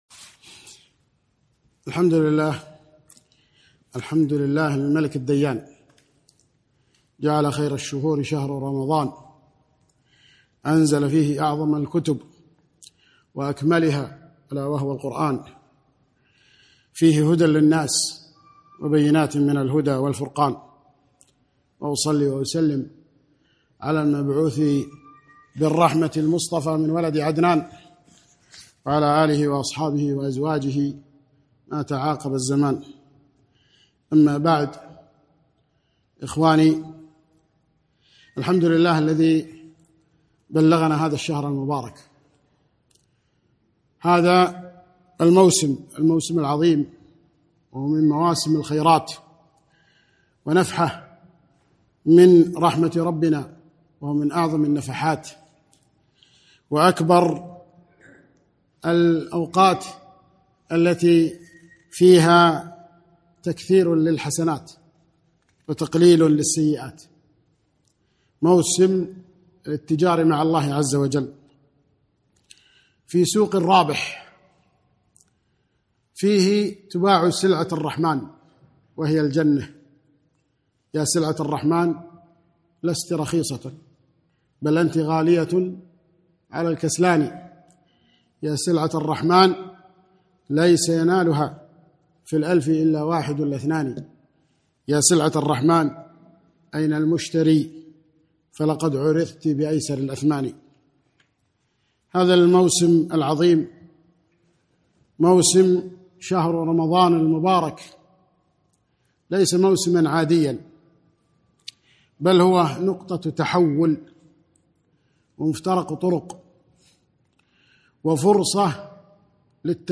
محاضرة - رمضان موسم التغيير